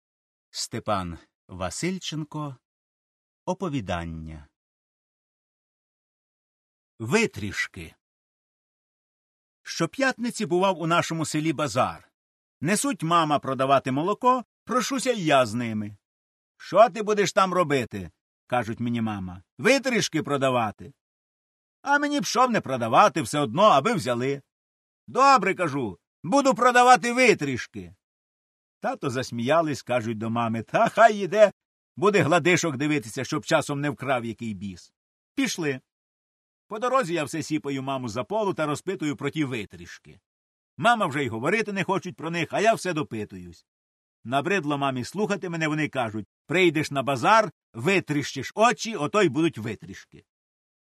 Каталог -> Аудио книги -> Классическая
Текст подан без сокращений.